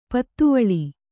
♪ pattuvaḷi